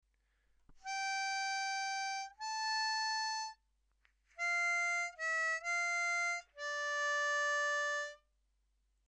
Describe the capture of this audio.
We have chopped the tune up into small chunks to help you.